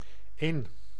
Ääntäminen
IPA: /ɪn/